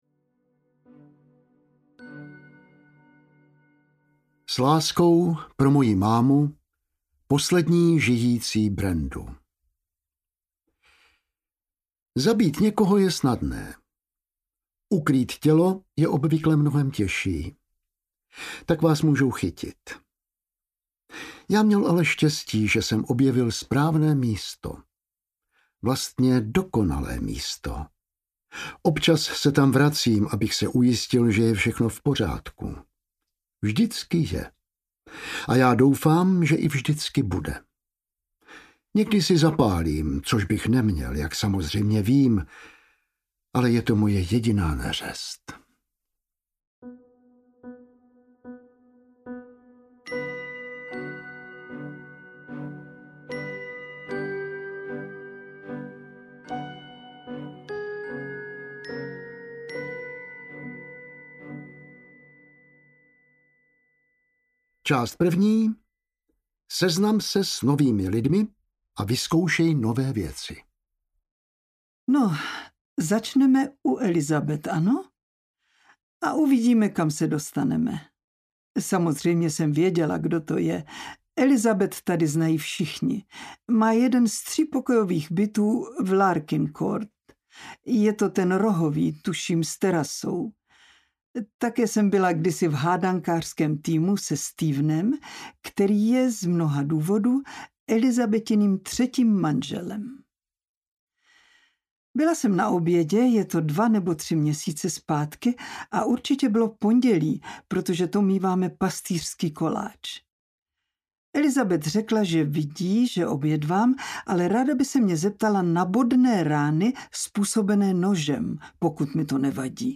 Čtvrteční klub amatérských detektivů audiokniha
Ukázka z knihy
• InterpretLibuše Švormová, Jan Vlasák